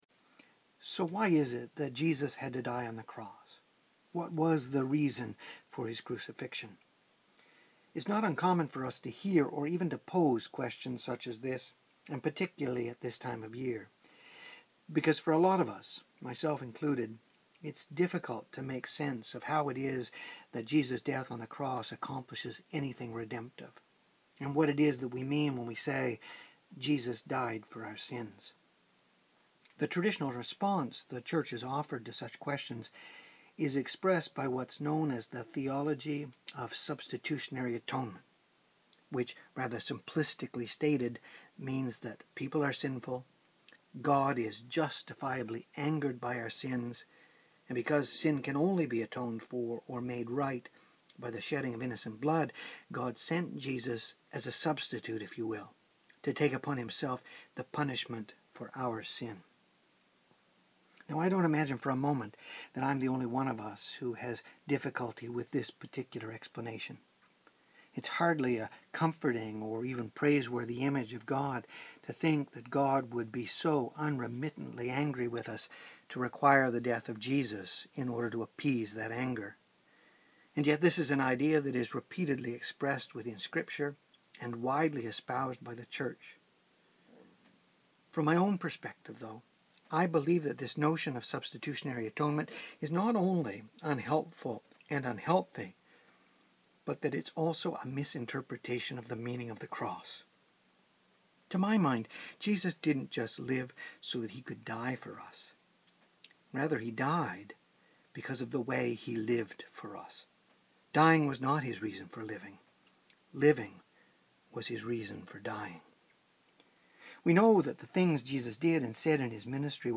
Sermons | St John the Evangelist
Good Friday